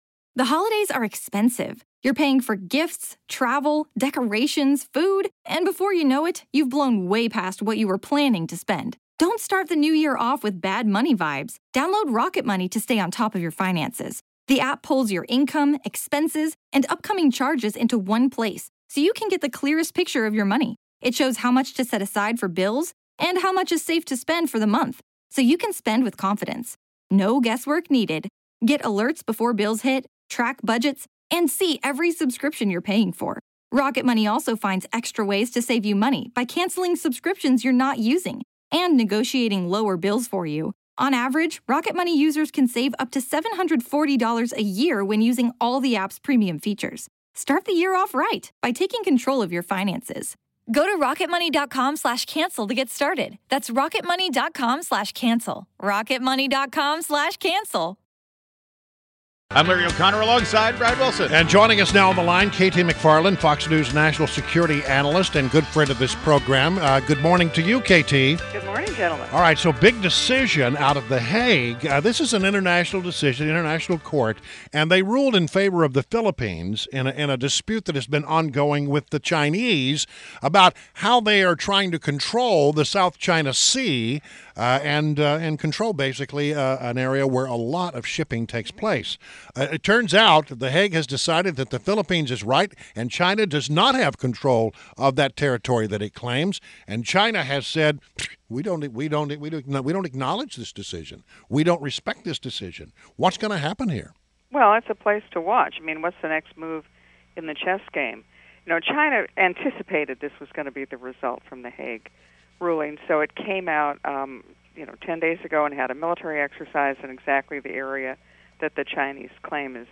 WMAL Interview - KT McFarland - 07.13.16